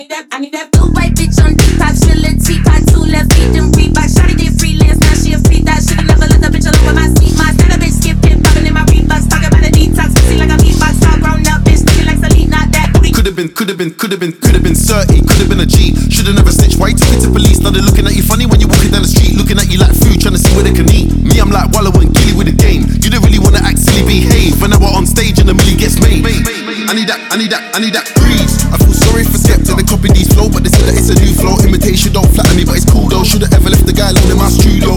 Electronic Dance
Жанр: Танцевальные / Электроника